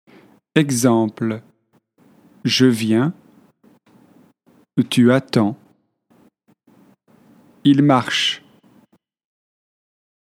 Groupe rythmique - leçon 1
📌 Avec un pronom sujet, on prononce sans pause :